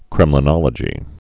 (krĕmlə-nŏlə-jē)